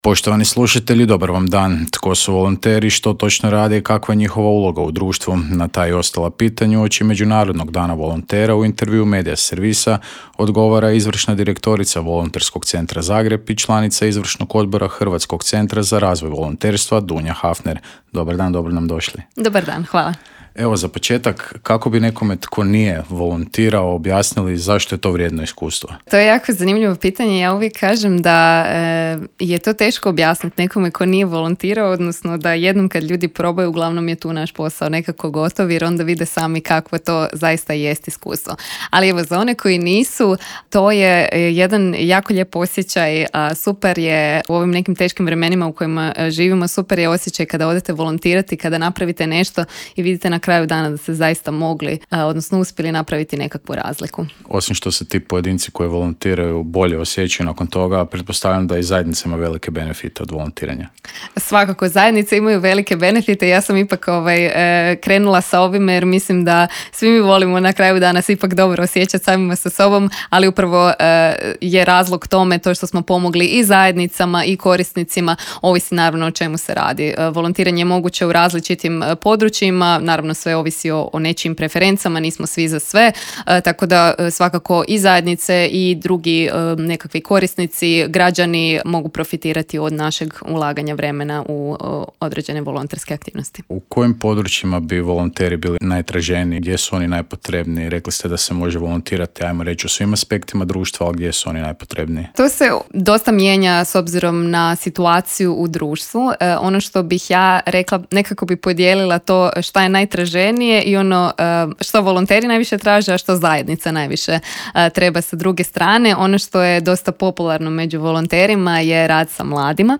ZAGREB - Tko su volonteri, što točno rade i kakva je njihova uloga u društvu? Na ta i ostala pitanja uoči Međunarodnog dana volontera u intervjuu Medi...